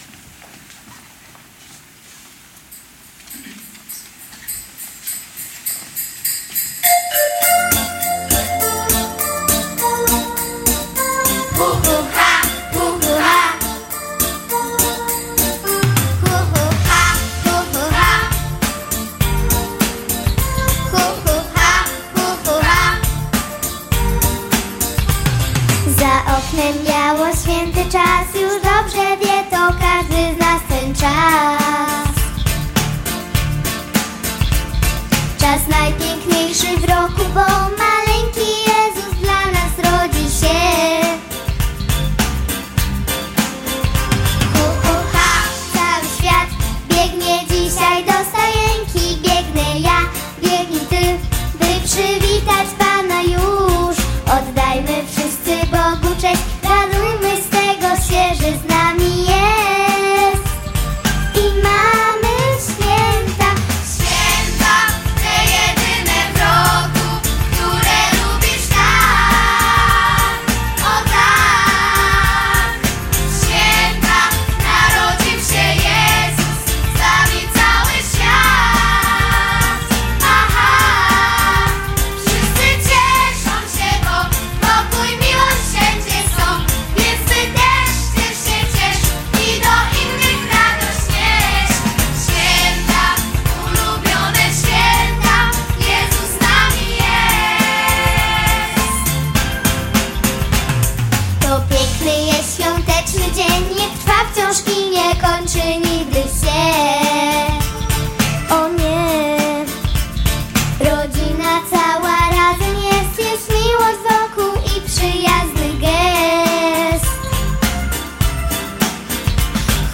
XIII Diecezjalny Festiwal Kolęd i Pastorałek – finał – Młodzieżowy Dom Kultury im.
Pastorałka ” Święta, ulubione święta”
Wykonała brawurowo, kolorowo i z entuzjazmem aż dwie pastorałki „Świąta, ulubione święta” oraz życzyli wszystkim „Szczęśliwych dni”.